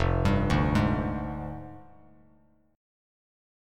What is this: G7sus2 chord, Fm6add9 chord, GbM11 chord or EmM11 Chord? Fm6add9 chord